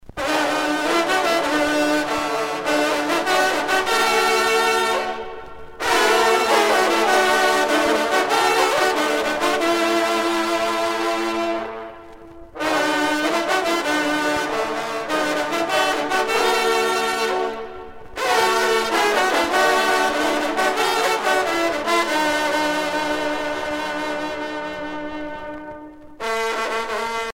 trompe - fanfare - personnalités
circonstance : vénerie
Pièce musicale éditée